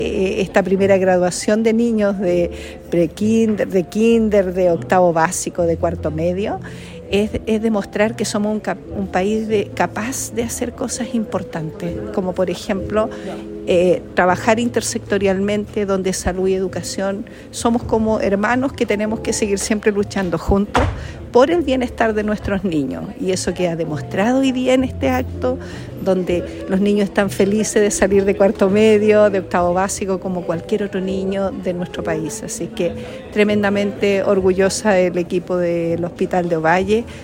Paola-Salas-Seremi-de-Salud-Coquimbo.mp3